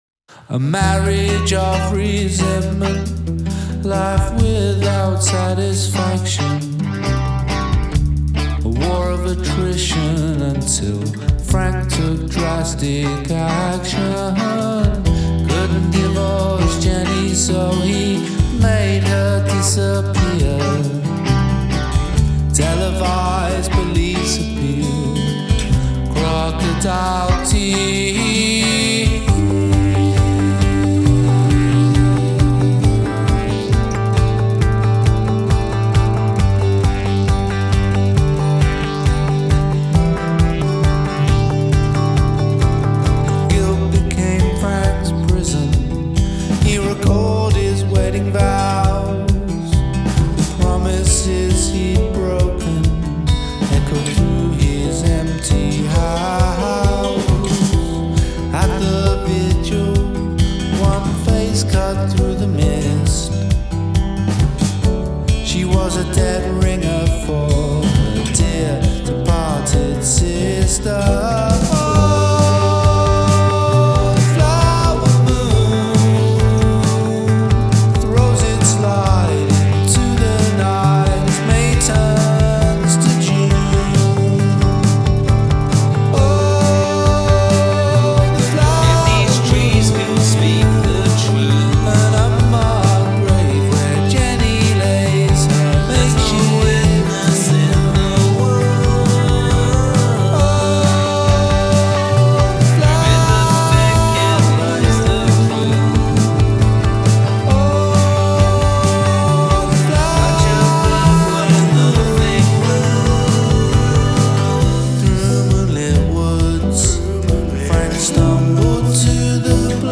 Narrative song about a murder